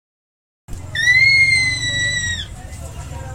使用USB Mic和Audacity录制。
标签： 女孩 性感 搞笑 语音 英语 女性 疯了 美国 声乐 生气 这样做 大喊大叫 女人 什么
声道立体声